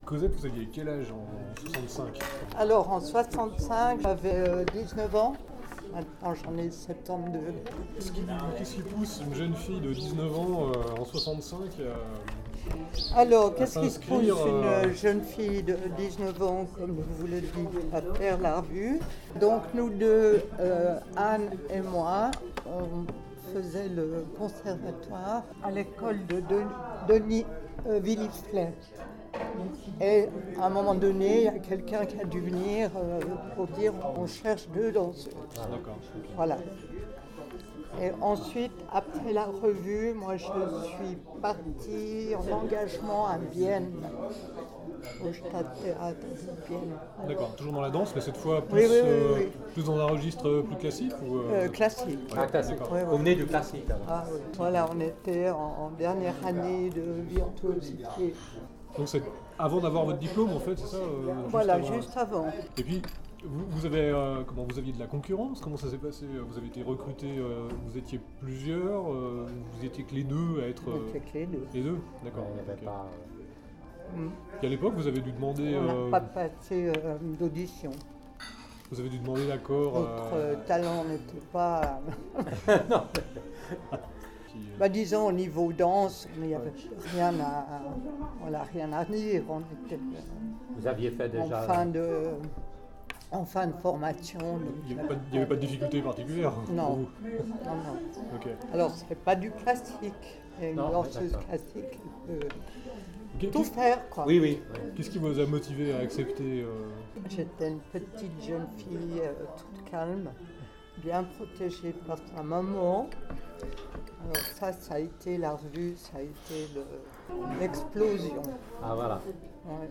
au Café Dorian, à Genève, le 29 octobre 2019